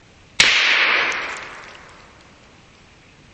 一名军官使用警笛维护秩序。
Tag: 喇叭 车辆 音乐 双耳记录 俄国 实地录音 汽车 宁静的 警察